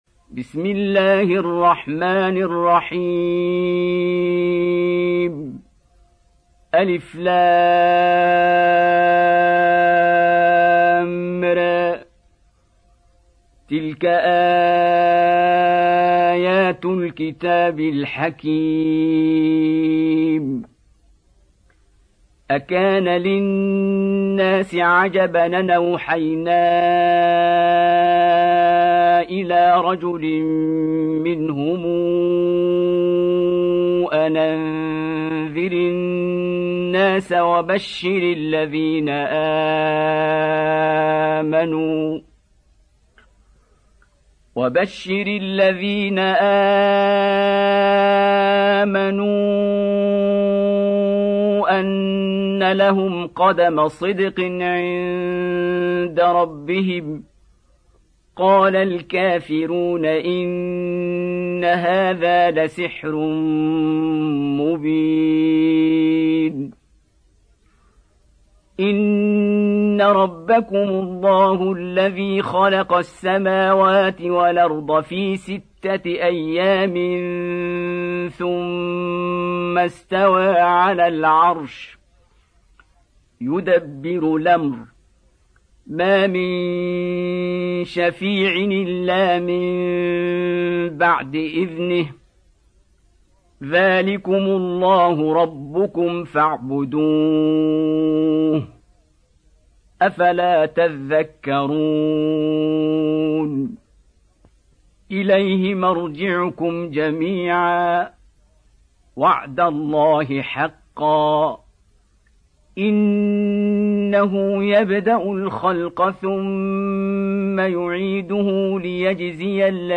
Surah Yunus Beautiful Recitation MP3 Download By Qari Abdul Basit in best audio quality.